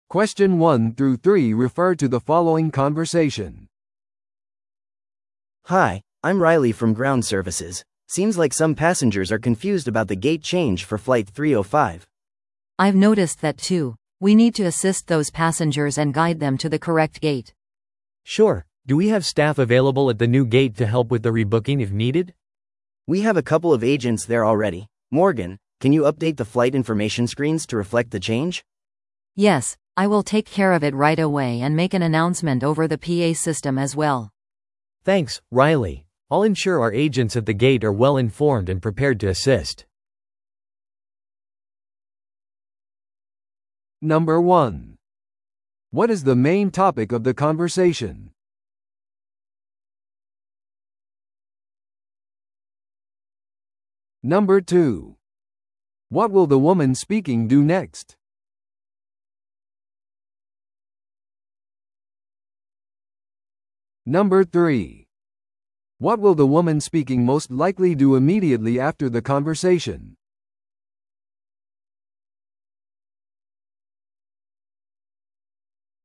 No.1. What is the main topic of the conversation?
No.2. What will the woman speaking do next?